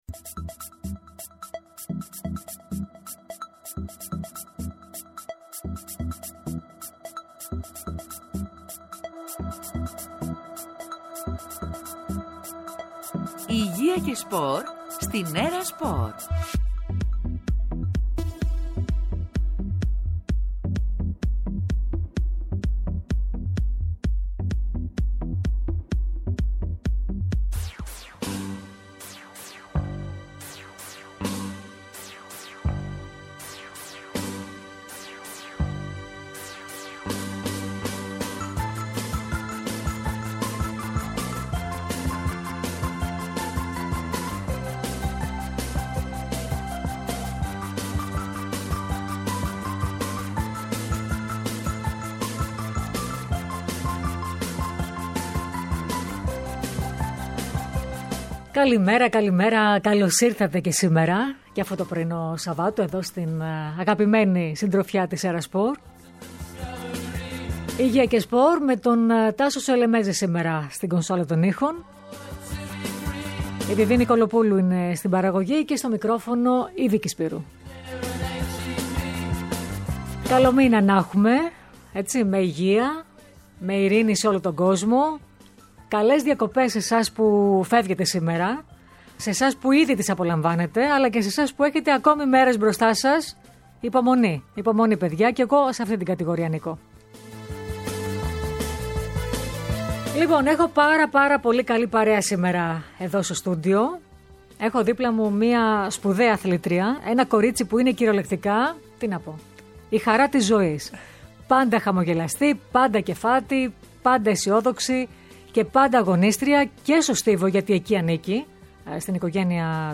Την κορυφαία μας άλτρια του ύψους, Τατιάνα Γκούσιν, φιλοξενήσανε στην ΕΡΑ ΣΠΟΡ και στην εκπομπή «Υγεία και σπορ».